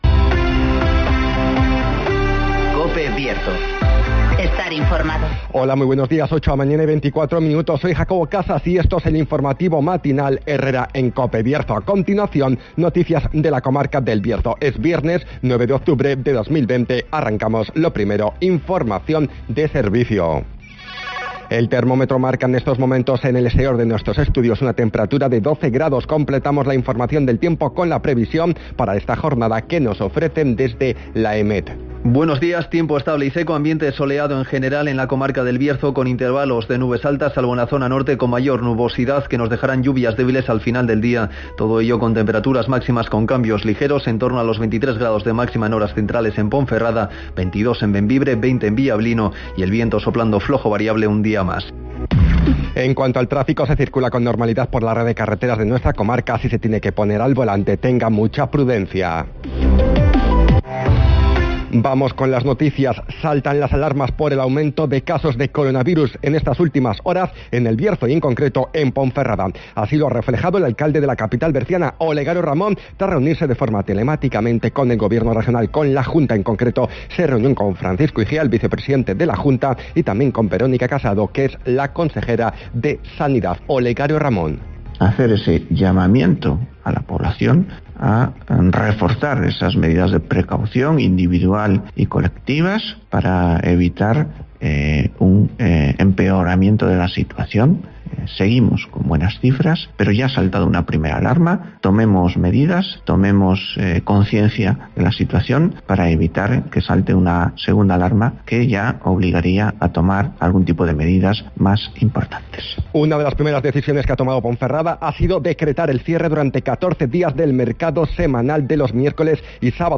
INFORMATIVOS
Conocemos las noticias de las últimas horas de nuestra comarca, con las voces de los protagonistas.
-Saltan las alarmas en el Bierzo por el aumento de casos de Covid-19 en las últimas horas (Declaraciones del alcalde de Ponferrada, Olegario Ramón)